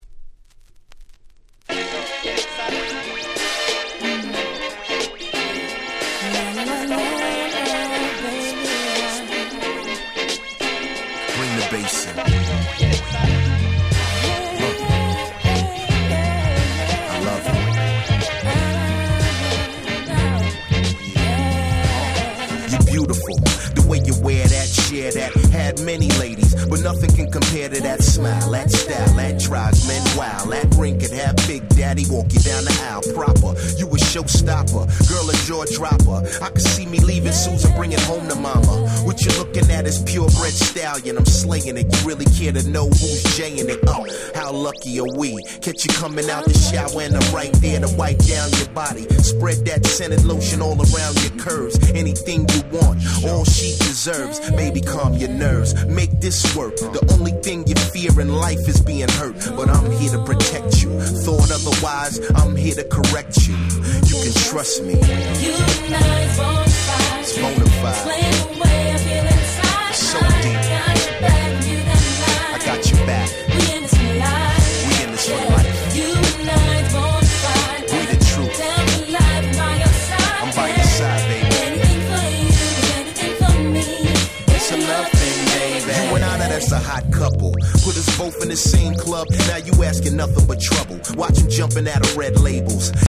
04' Very Nice Hip Hop !!
00's ブーンバップ